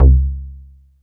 PROMOOG C2.wav